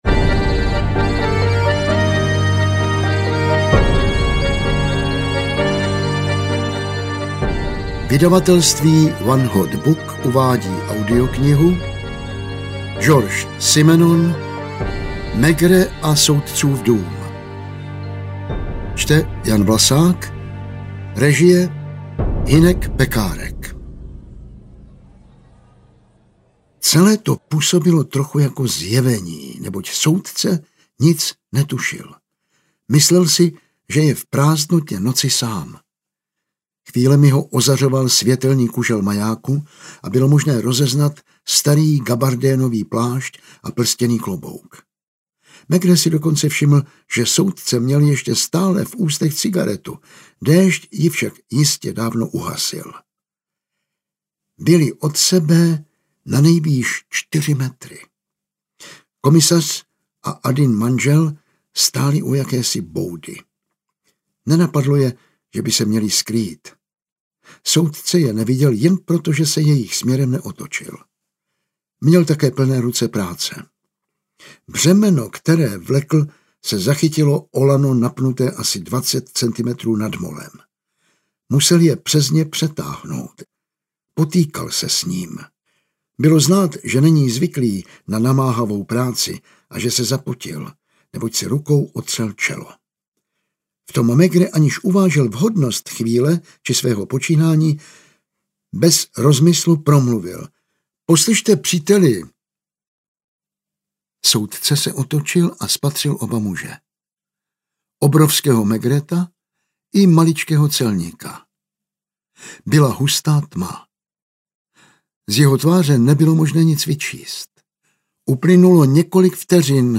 Ukázka z knihy
• InterpretJan Vlasák